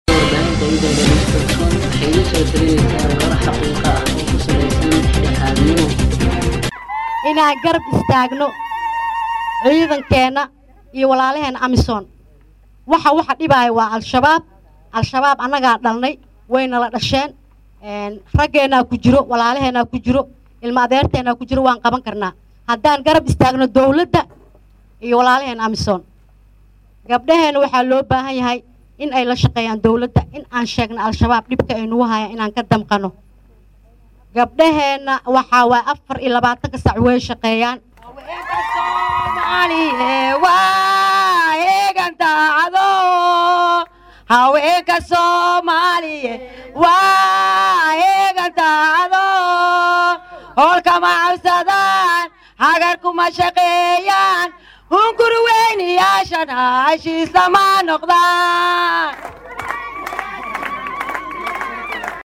Baydhabo(INO)-Magaalada Baydhabo ee Gobalka Bay  ayaa habeenkii xalay ahaa waxaa looga dabaal degay Munaasabadda 1-da Luuliyo oo ah markii ay xuroobeen gobalada Koonfureed ee Soomaaliya islamarkaana ay midoobeen gobalada Waqooyi iyo Koonfur Soomaaliya.
Munaasabadda waxaa Lagu soo Bandhigey Tix Gaboy ah taas oo Gilgilisey Laabta Dadkii Dhageysanayey,Wasiirka Haweenka ee KG Soomaaliya oo halkaasi Khudbada ka jeedisey ayaa ka hadashey Muhiimada Midnimada iyo ilaalinta Xaquuqda Haweenka KGS.
Halkan Dhageyso Khudbaddii Wasiirka Haweenka KGS